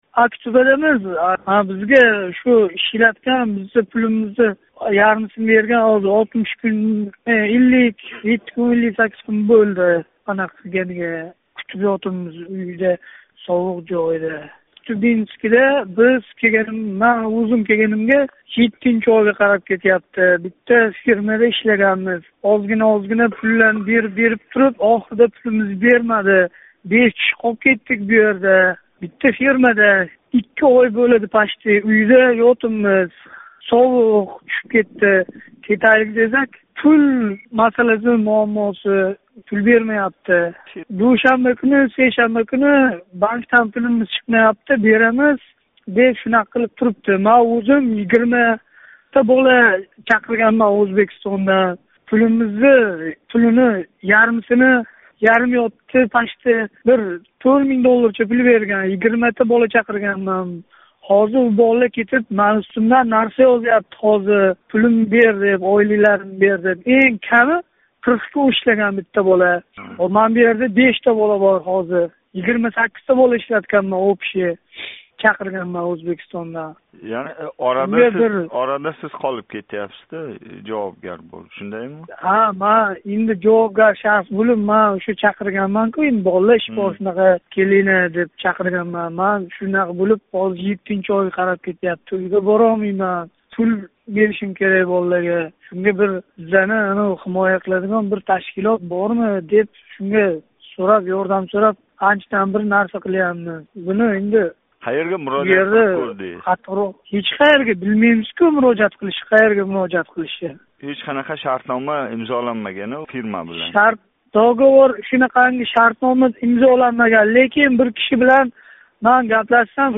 билан Озодлик радиоси